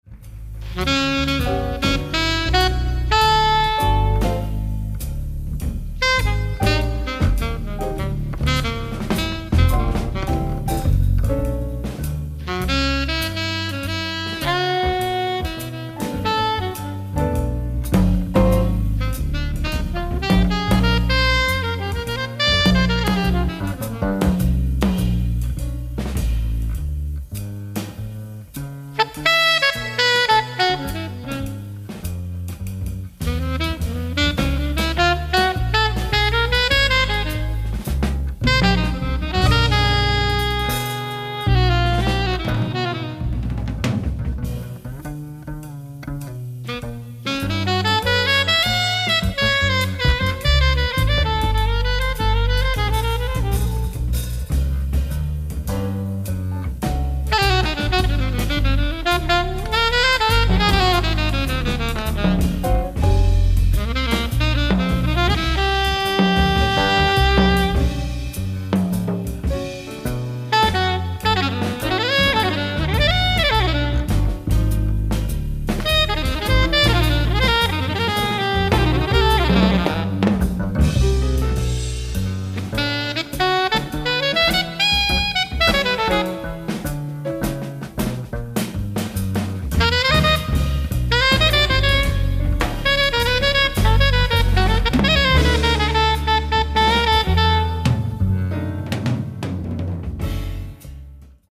ライブ・アット・カンプナゲル、ハンブルグ、ドイツ 10/27/1991
最高のライブを最高の音質で体感出来ます！！
※試聴用に実際より音質を落としています。